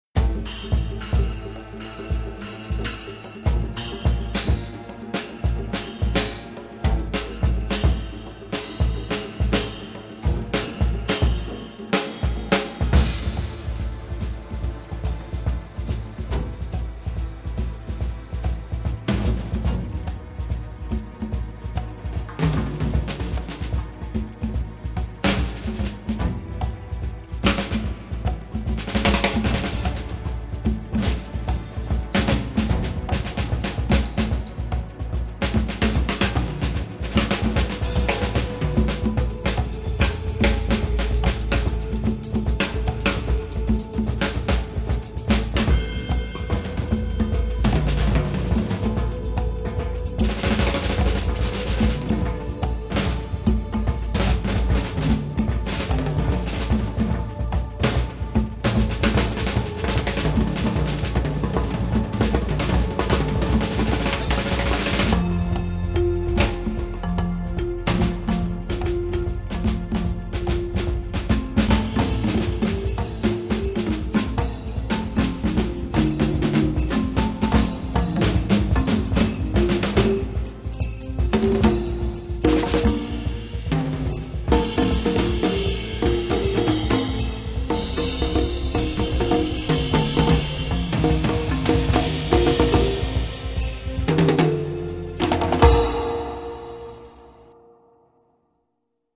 Drums+Playback